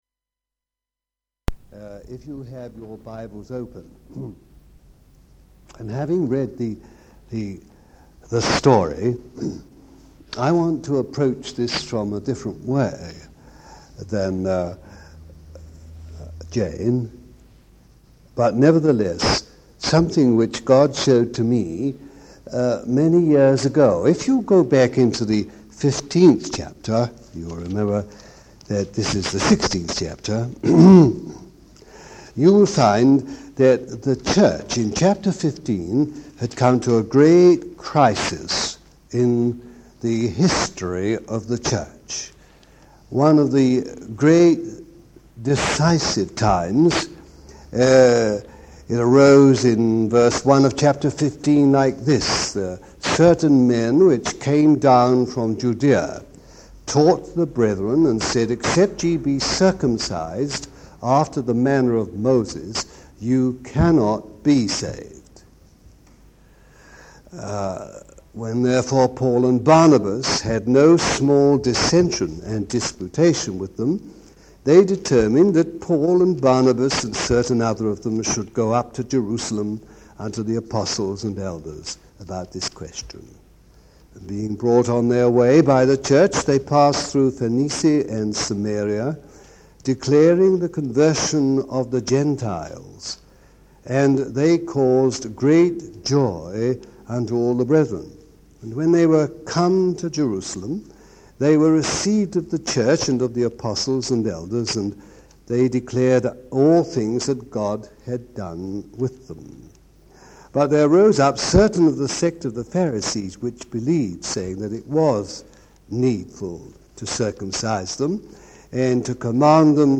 Message: “Praying Women
The message was given to a Women's Meeting